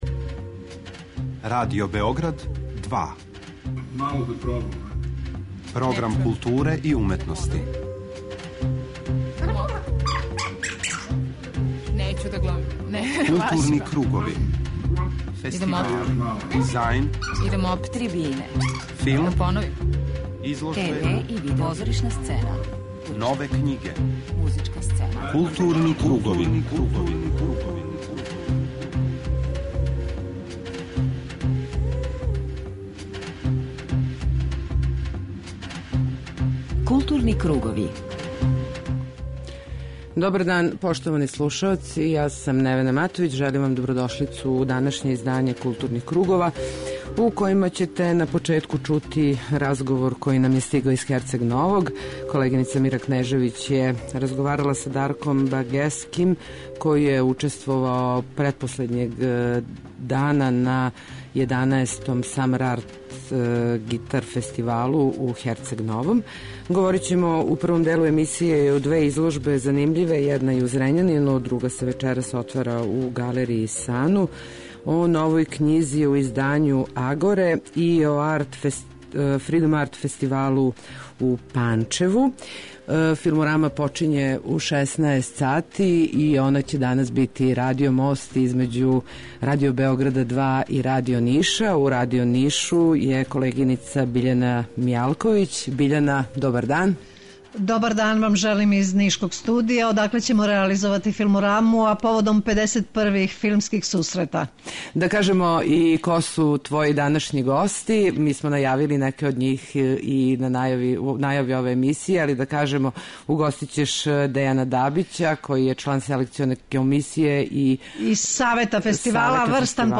Овог уторка Филмораму реализујемо заједно са колегама из Радио Ниша.